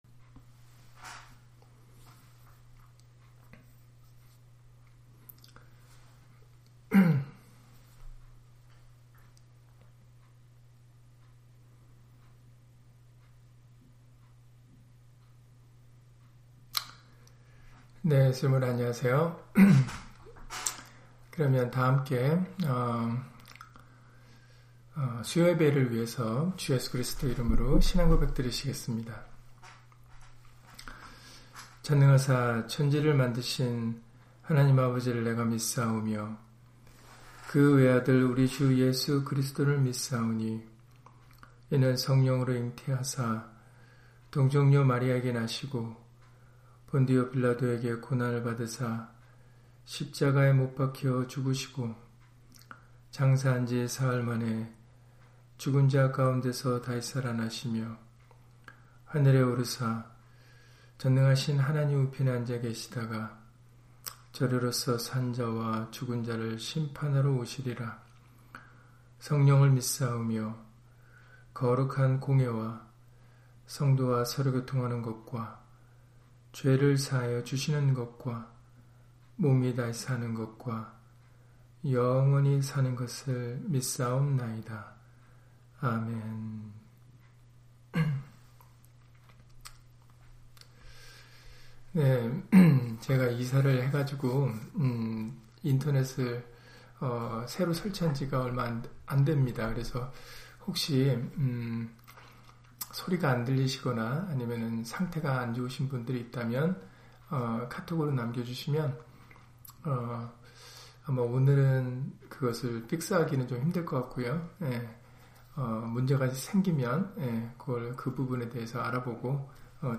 다니엘 2장 1-23절 [하늘에 계신 하나님] - 주일/수요예배 설교 - 주 예수 그리스도 이름 예배당